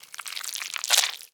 Flesh Squish 2 Sound
horror